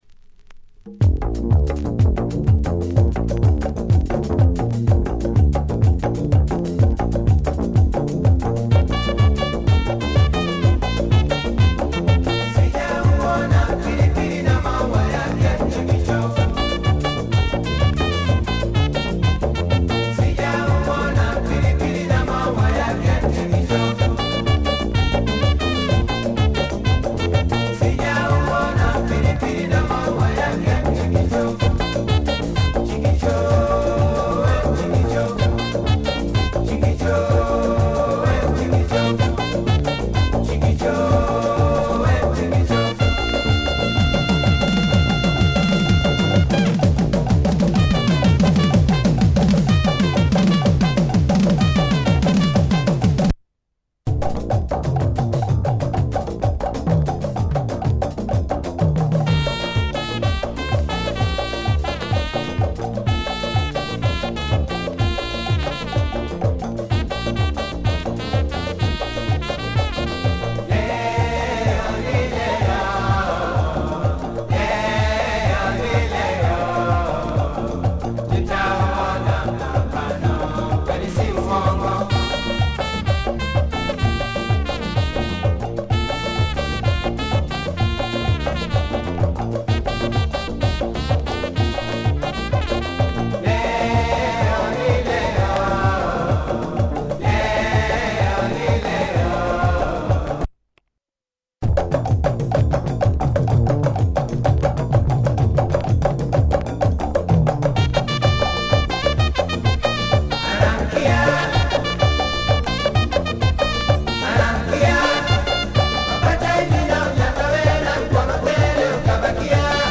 This copy has some background noise but plays faily fine.